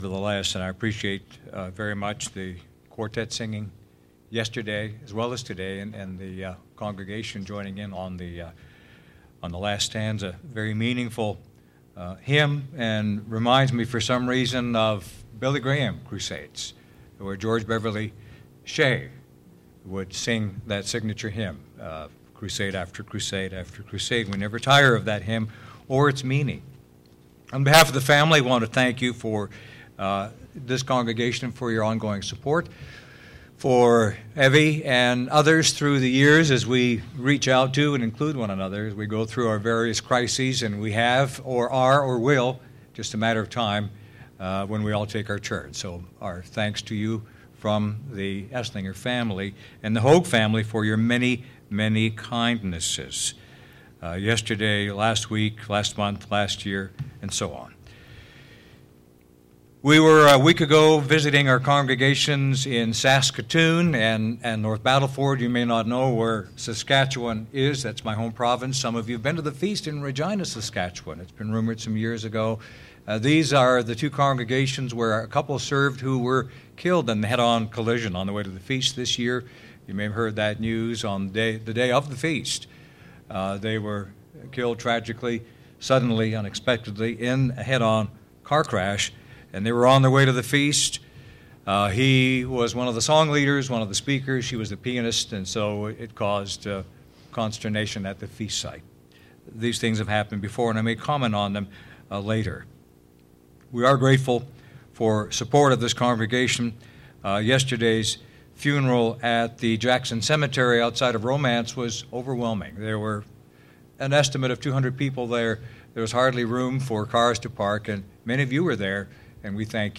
This sermon looks into a biblical perspective on how to deal with tragedies and how we as Christians can overcome..